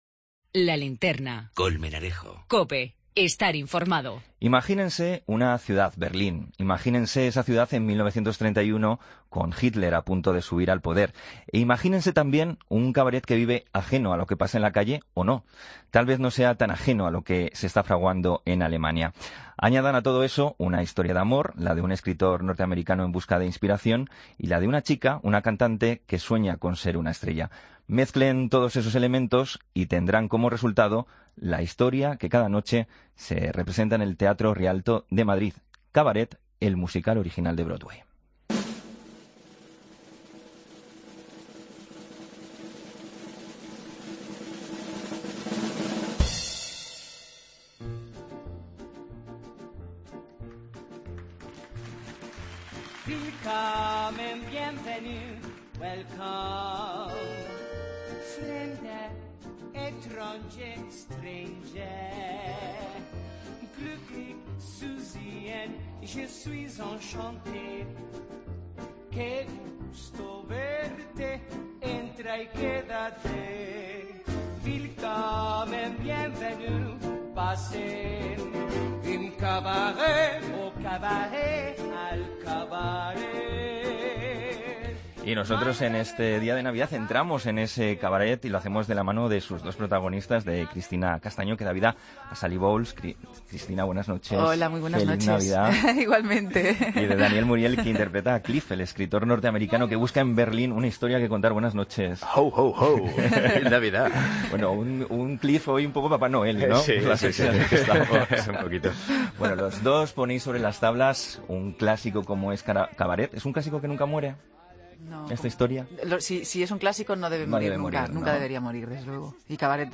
AUDIO: Los actores nos hablan de 'Cabaret', el clásico musical que ambos protagonizan en el teatro Rialto